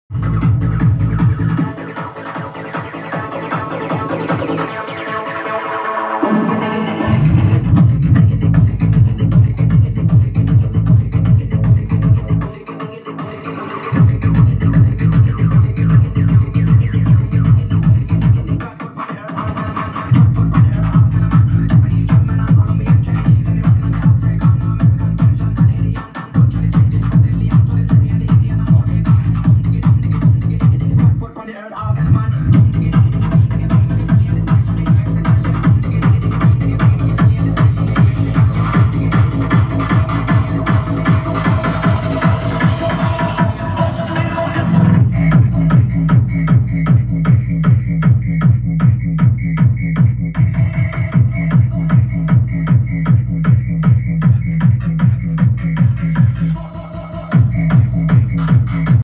15 Minutes Of High Energy Bouncing Techno